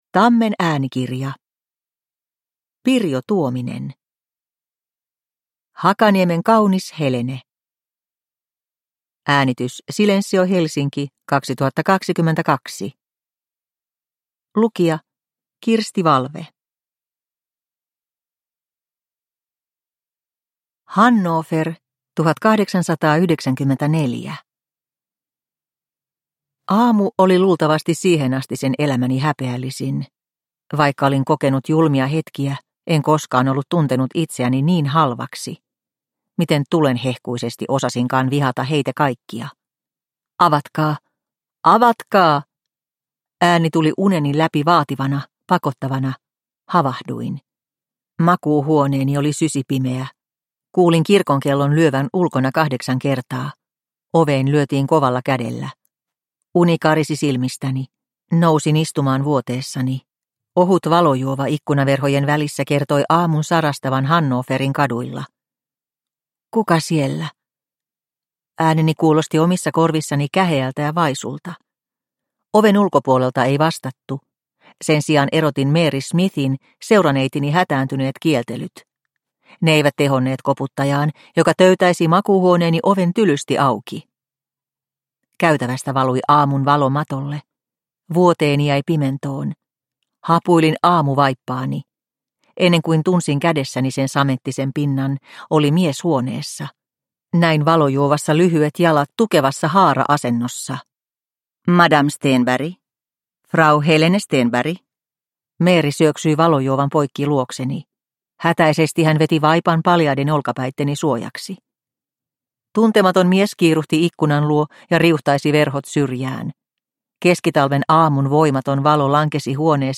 Hakaniemen kaunis Helene – Ljudbok – Laddas ner